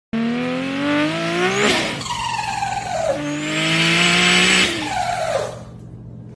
Toyota Supra Mk4 Blow Off Sound Effects Free Download